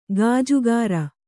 ♪ gājugāra